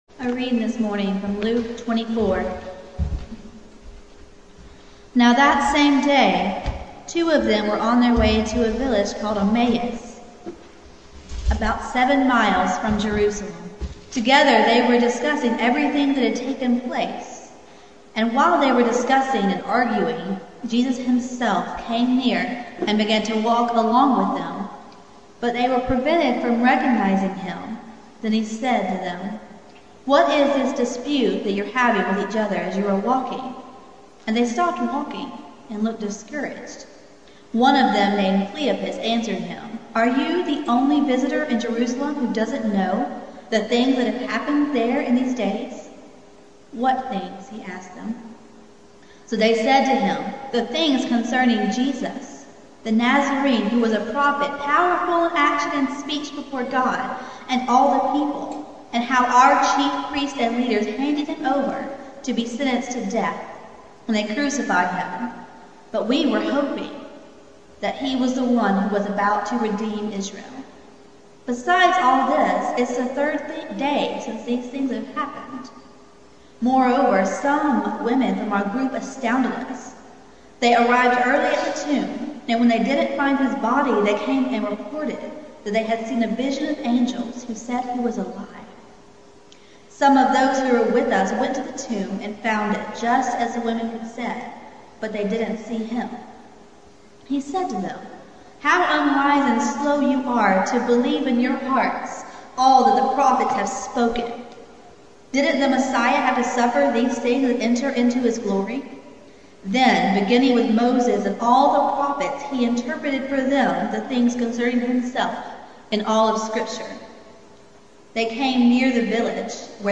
Passage: Luke 24:1-26 Service Type: Sunday Morning